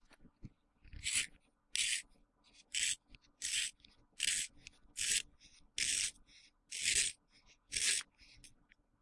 削铅笔机
描述：将铅笔插入手摇卷笔刀中......听到锐化，铅笔被取出。立体声 在16位内部使用44.1 KHz ..使用Sony MiniDisc recorder 2009录制
标签： 起动-sharpener 研磨 手曲柄笔刨 锐化铅笔 手动笔刨
声道立体声